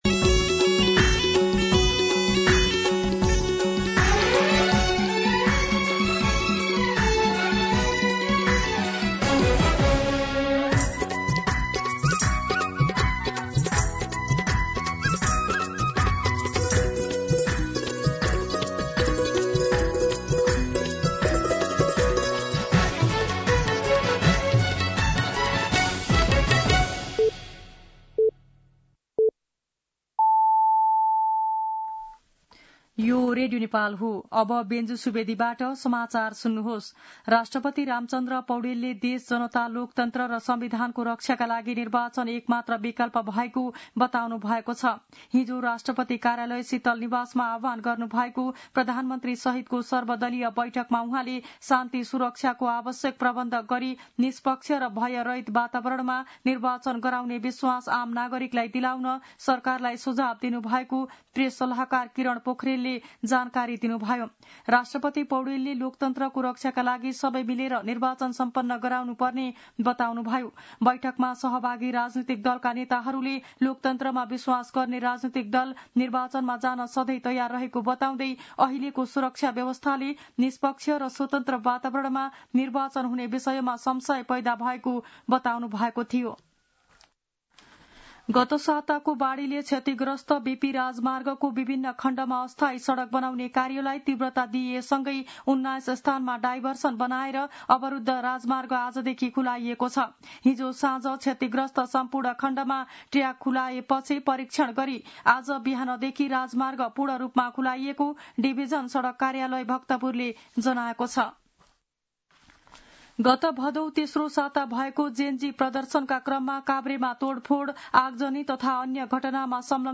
मध्यान्ह १२ बजेको नेपाली समाचार : २५ असोज , २०८२
12-pm-Nepali-News-2.mp3